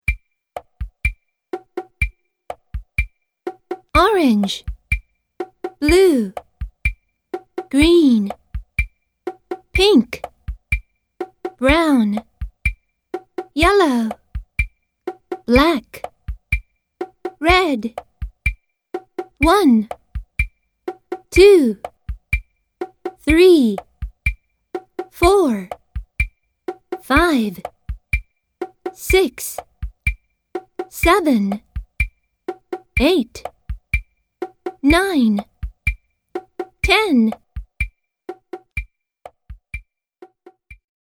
3 More vocabulary song word reading tracks